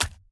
Sfx_creature_rockpuncher_walk_slow_left_legs_02.ogg